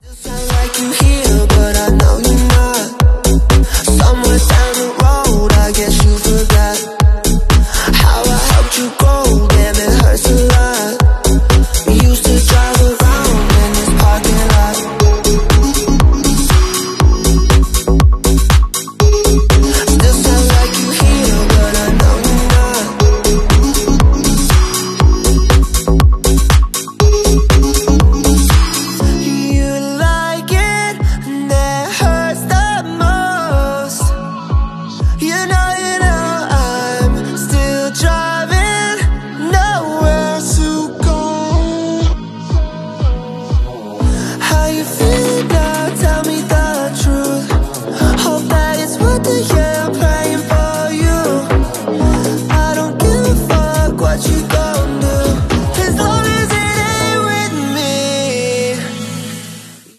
Gsxr 1000 k5 sounds amazing 😁❤ sound effects free download